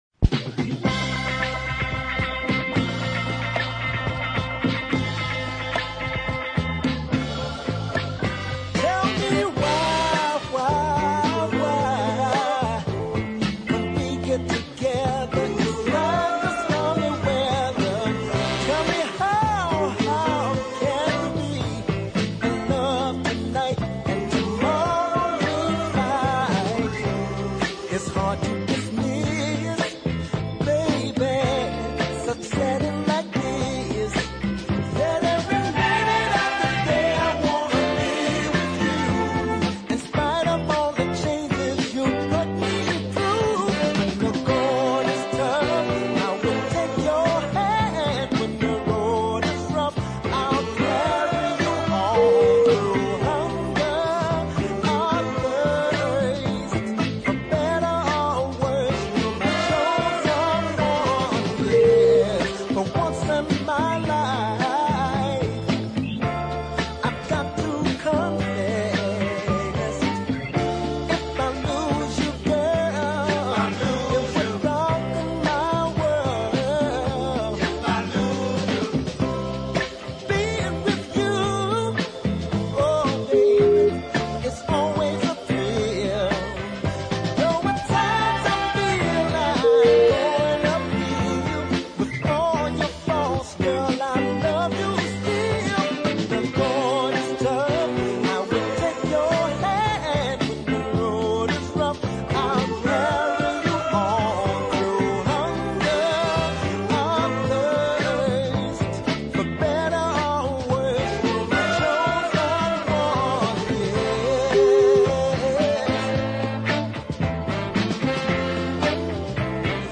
melodic
flexible and with a lovely sense of timing.